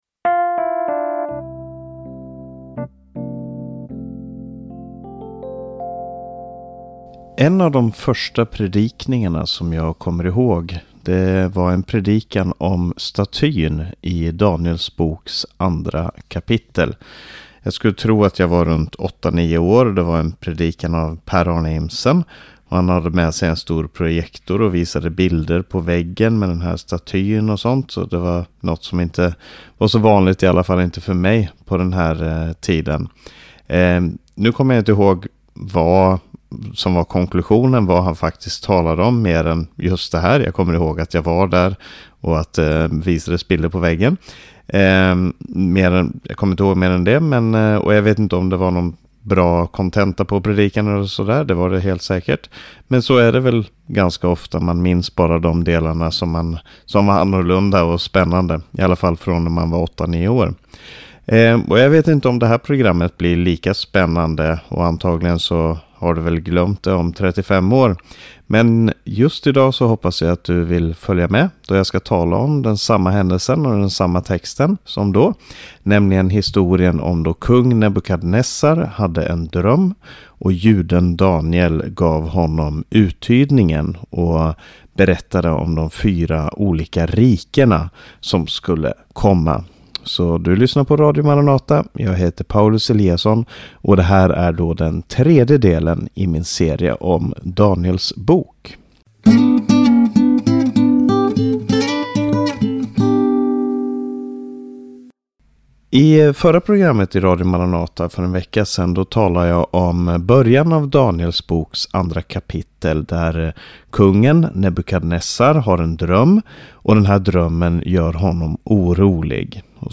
Bibelstudium över Daniels bok.